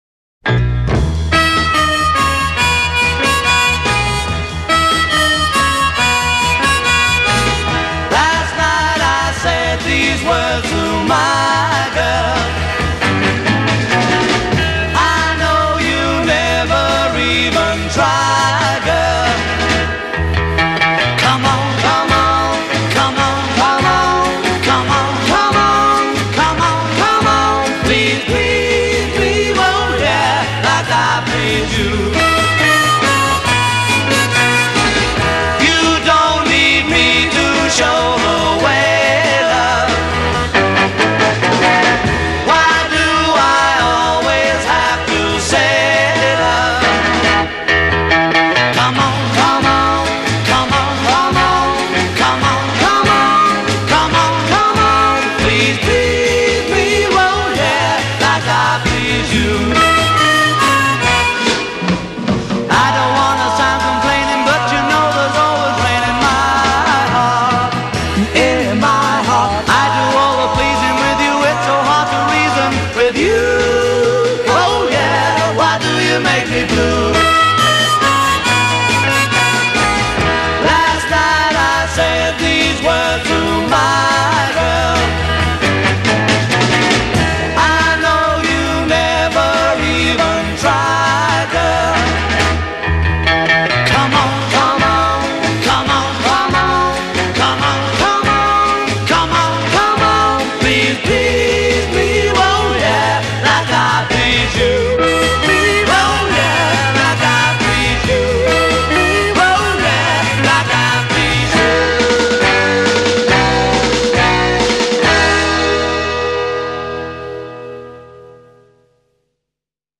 Intro* : 4 guitar/harmonica theme w/ drum break
B Chorus : 16 solo vocal with response at end of 1st lines,